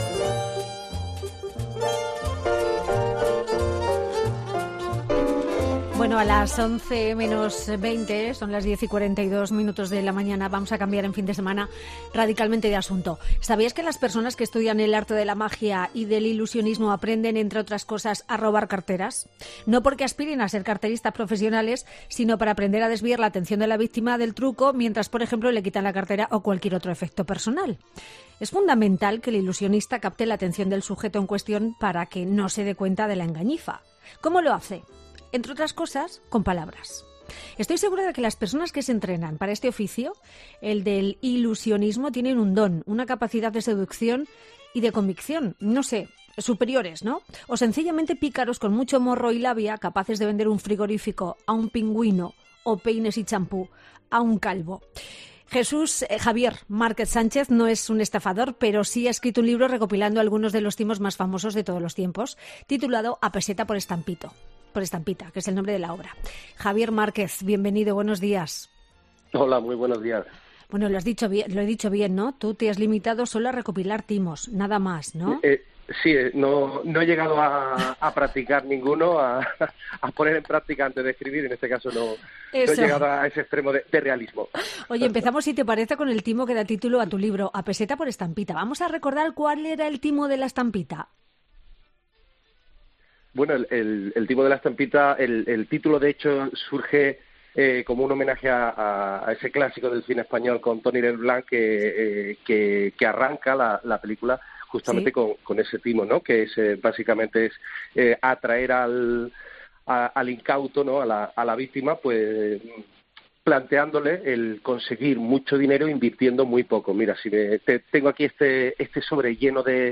una charla